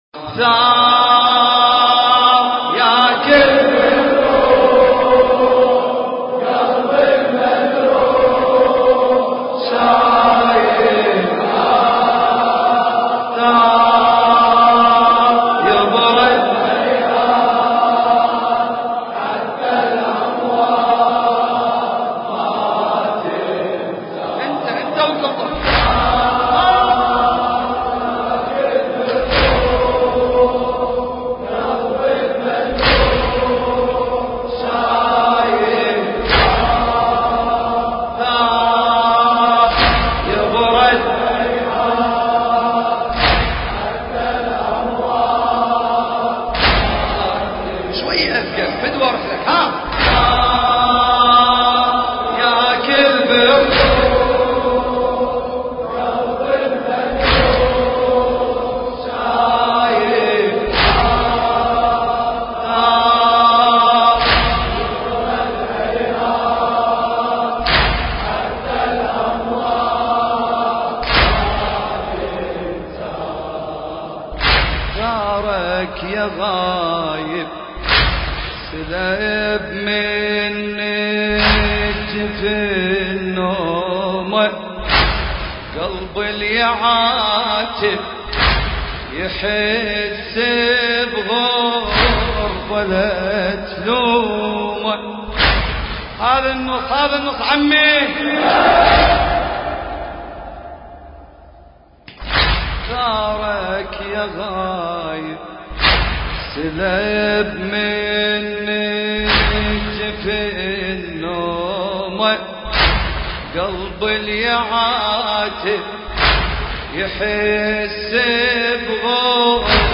المكان: حسينية الإمام الحسن المجتبى (عليه السلام) الكربلائية